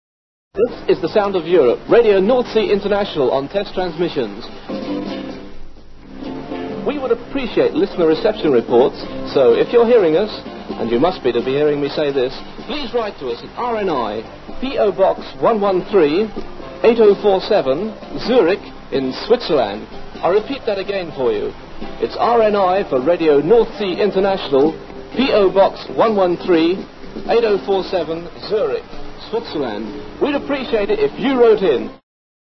Radio Northsea International Testing On Shortwave
Radio Northsea International sounded fantastic on 49 metres back then, perhaps a little too close to international distress frequencies.
rni-testing-on-shortwave.mp3